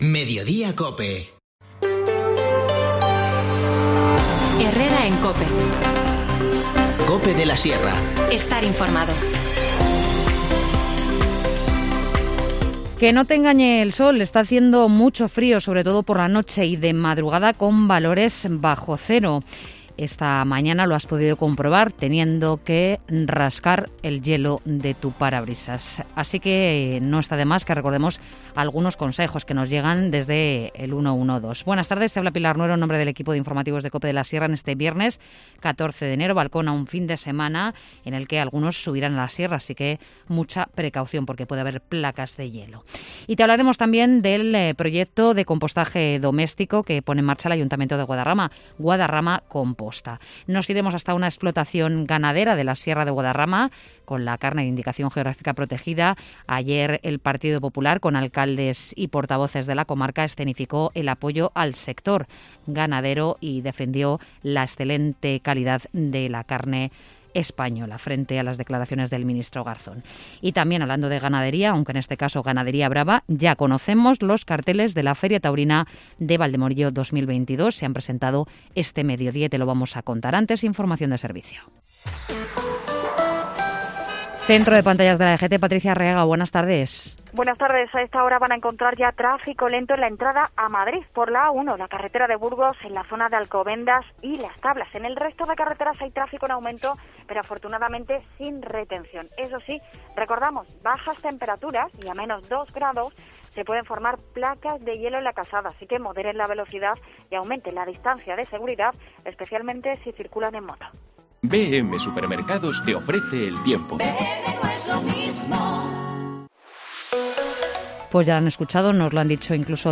Informativo Mediodía 14 enero